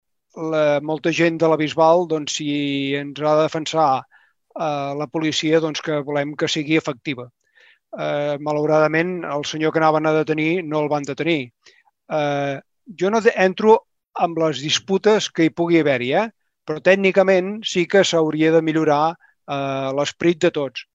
Tot plegat ho va explicar al Ple municipal celebrat aquest dimecres, a preguntes del regidor de l’oposició Xavier Dilmé.